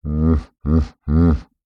animal
Hippopotamus Grunt